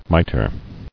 [mi·ter]